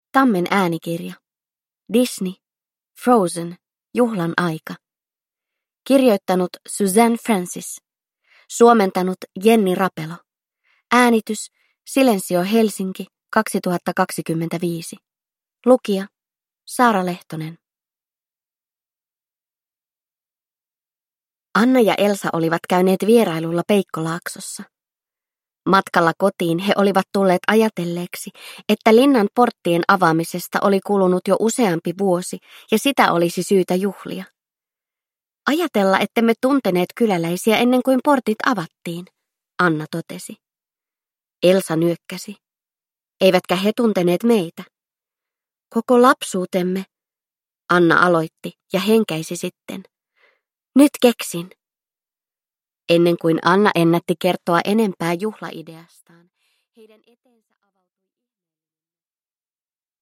Disney. Frozen. Juhlan aika (ljudbok) av Disney